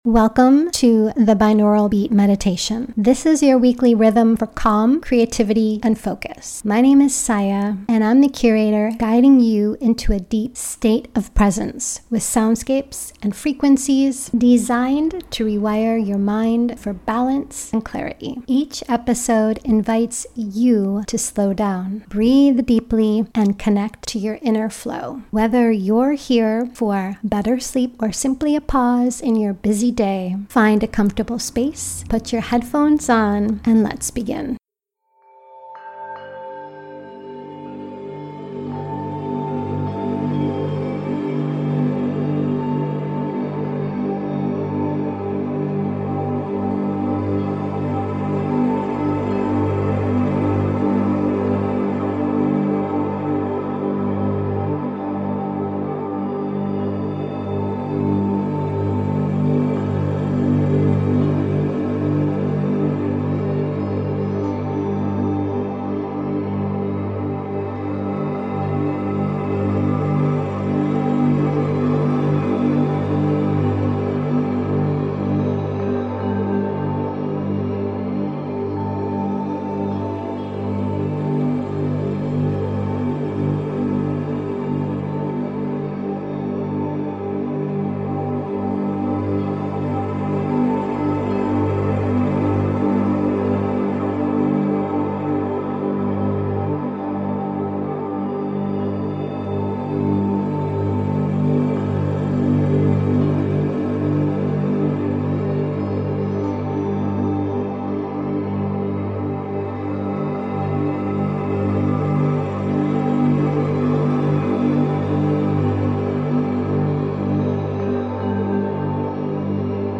13hz - Beta Waves - Full Moon Momentum: Illuminate Your Focus
Mindfulness, sound healing, and ritual — woven into every frequency.